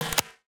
UIClick_Denied Negative Mechanical Hollow 02.wav